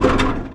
metal_rattle_spin_med_01.wav